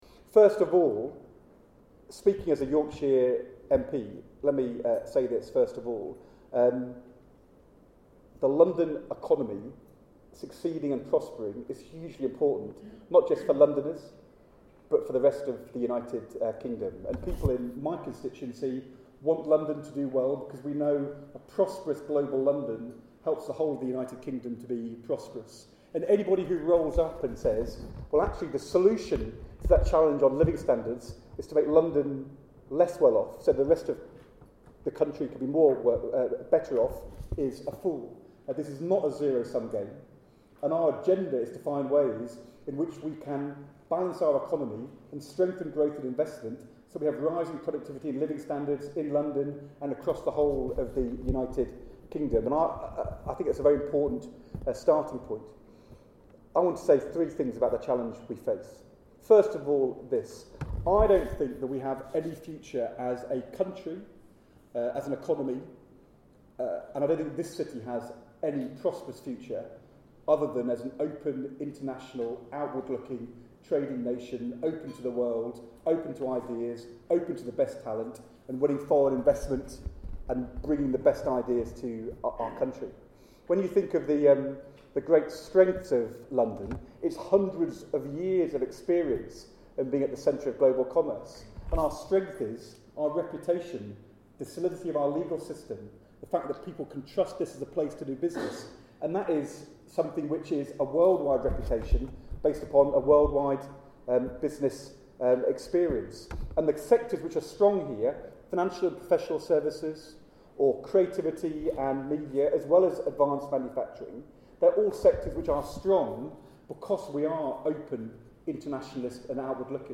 Rt Hon Ed Balls MP, Shadow Chancellor of the Exchequer, addresses London's business leaders on his party's plans for jobs, growth and economic prosperity.
At the London First lunch event he stressed: